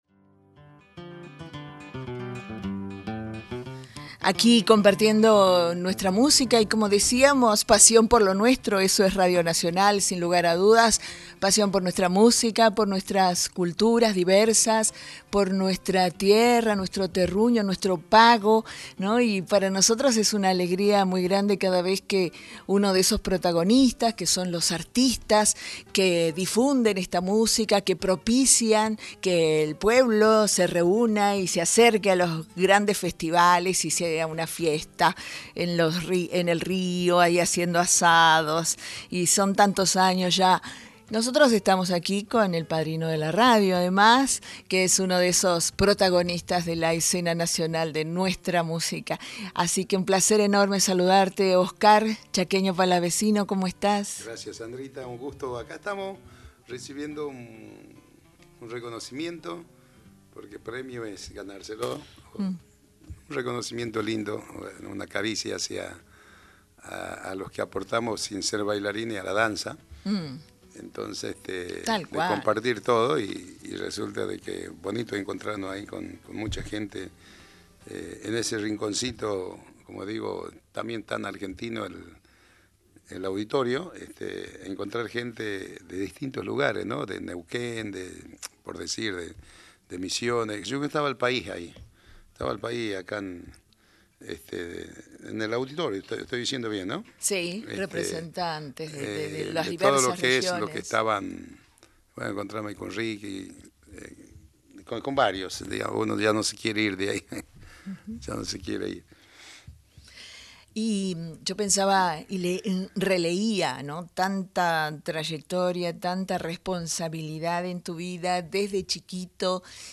El Chaqueño Palavecino en nuestros estudios